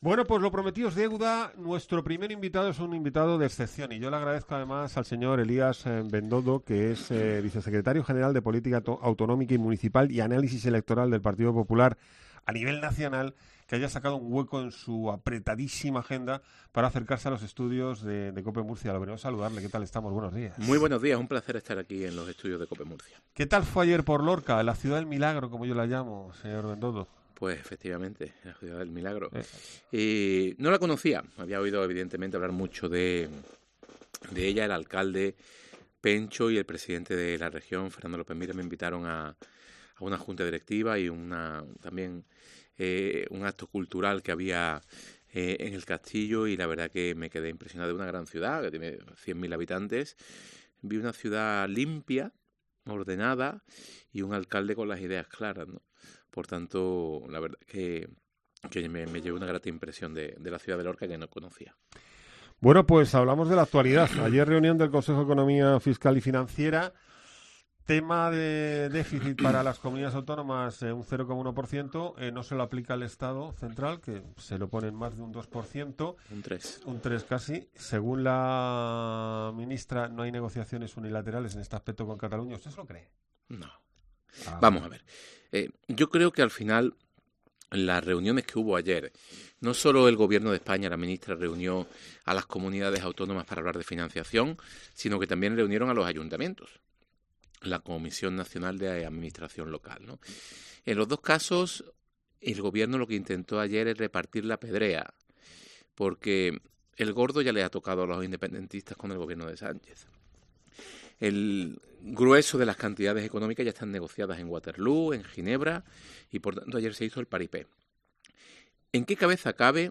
El vicesecretario General de Política Autonómica del PP también ha abogado en COPE MURCIA por una mesa nacional del agua para resolver las...